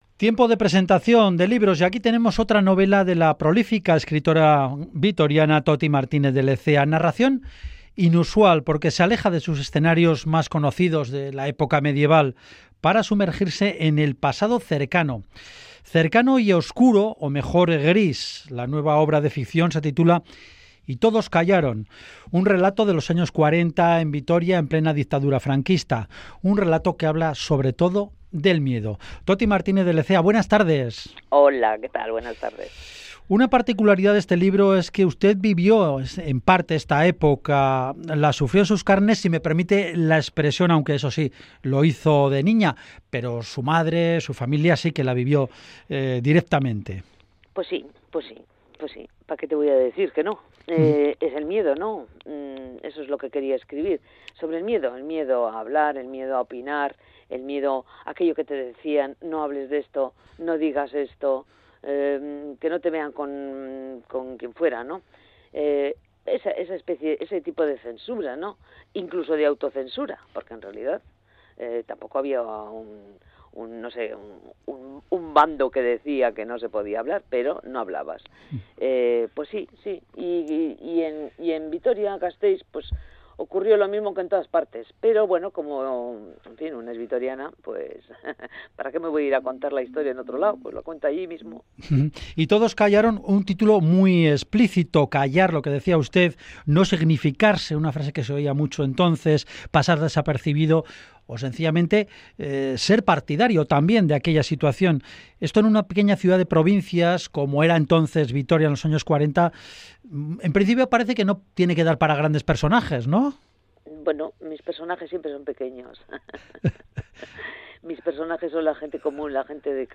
Entrevista a Toti Martinez de Lezea, su nuevo libro “Y todos callaron”